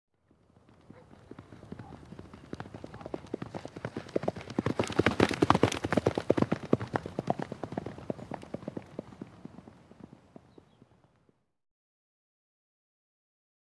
دانلود آهنگ اسب 14 از افکت صوتی انسان و موجودات زنده
دانلود صدای اسب 14 از ساعد نیوز با لینک مستقیم و کیفیت بالا
جلوه های صوتی